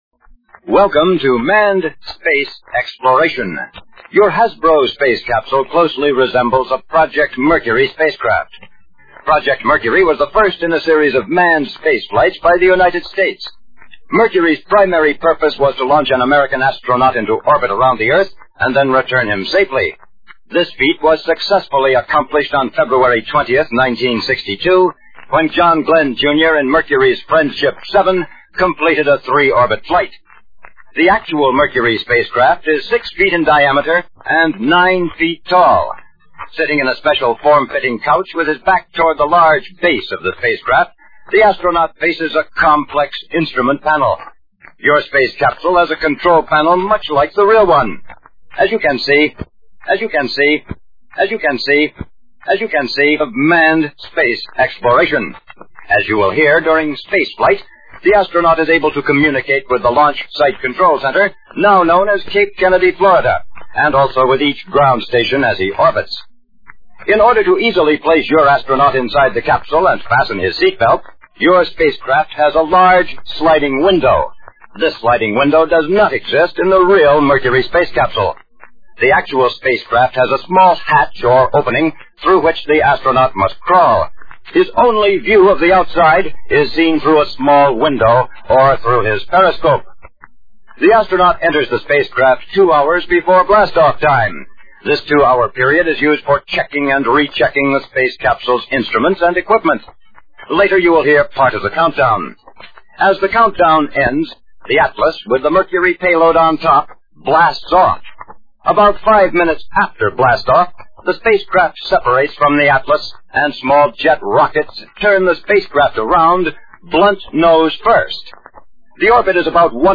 Also there were a series of books published by Peter Pan that included a vinyl recording of the story that a child could play while reading.